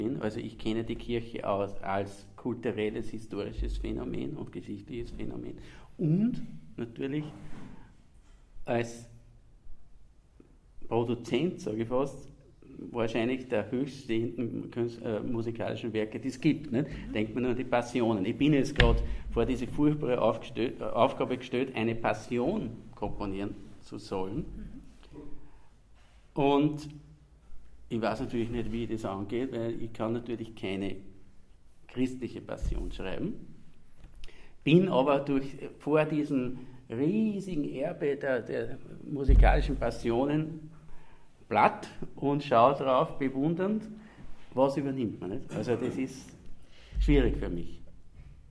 Über eine Ethik der Beschallung wurde beim Quartals.Gespräch am 27.9.2016 in der Stadtpfarre Linz genauso diskutiert, wie darüber welche Musik nun gut ist oder warum kirchliche Passionen große Vorgaben für heutige Komponisten sind.
Die lebendige Diskussion geben auch die Hörbeispiele wider.